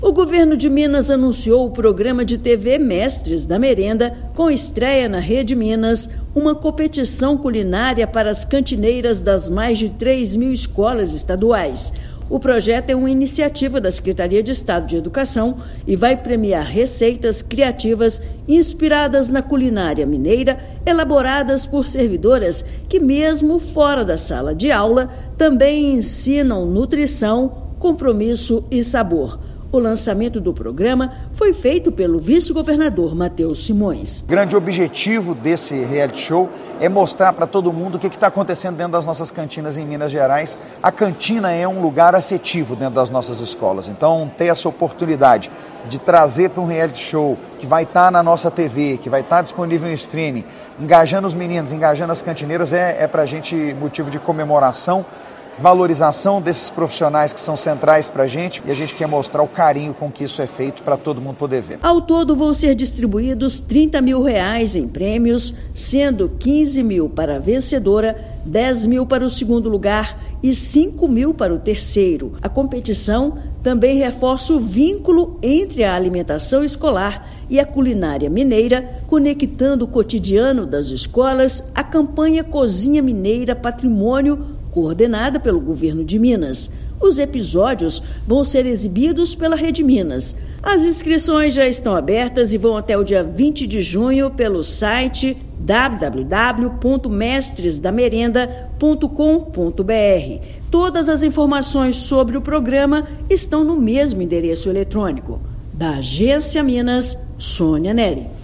Programa estreia em setembro na Rede Minas com foco na alimentação escolar e na culinária mineira. Ouça matéria de rádio.